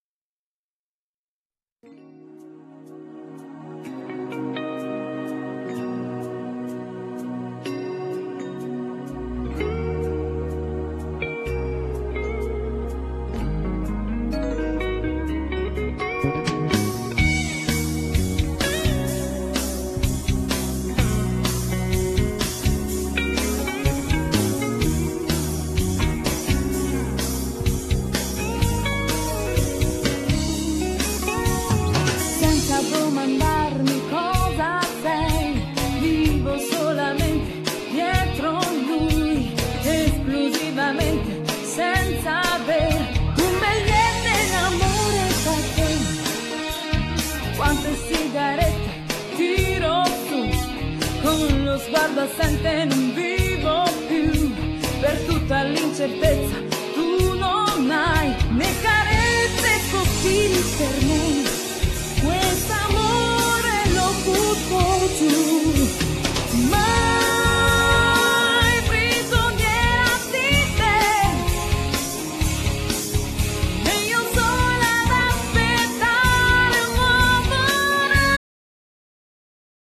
Genere : Pop Rock